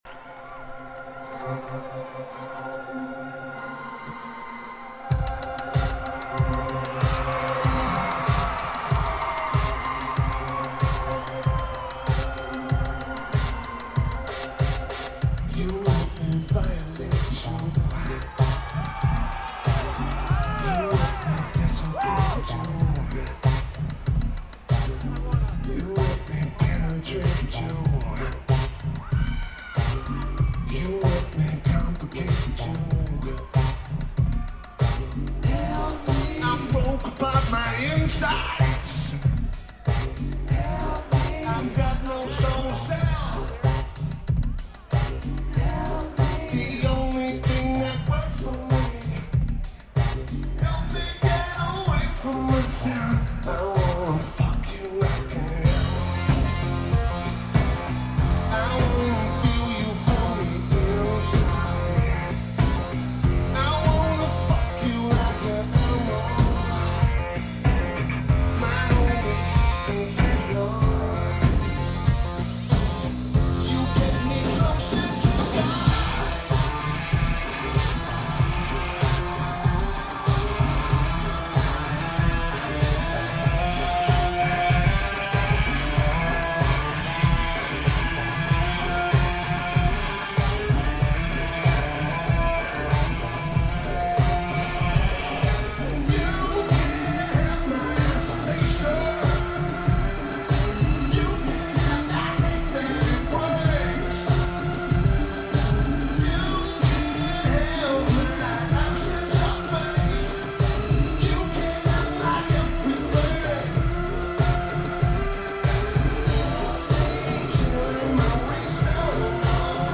Verizon Wireless Amphitheater
Guitar
Keyboards/Bass/Backing Vocals
Drums
Lineage: Audio - AUD (SP-CMC4c + SP-BB + Sony MZ-M100)
Notes: Great recording, one of the best of the tour.